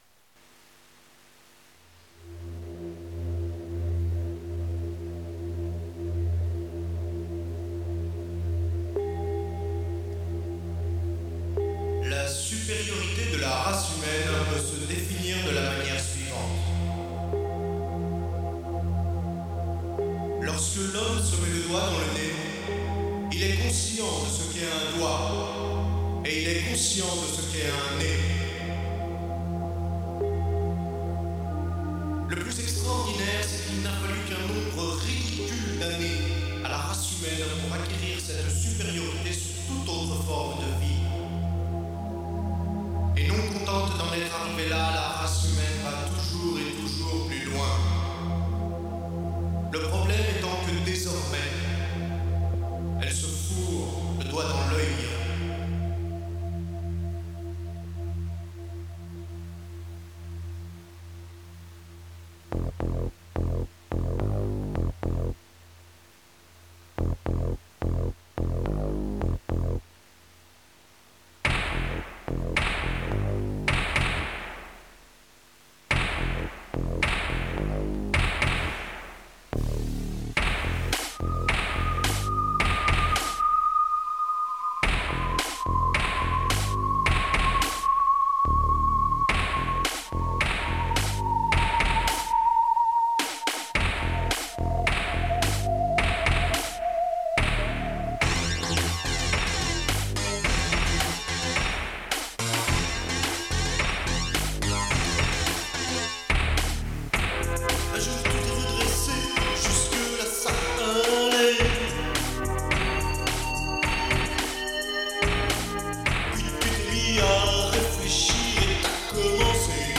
De nouveaux synthés
Au final un mixage très soufflant, mais des chansons que j’aime encore aujourd’hui.